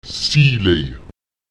iw [iù] wird wie das i in Igel artikuliert.
Lautsprecher ziwláy [ÈsiùlQI] das Licht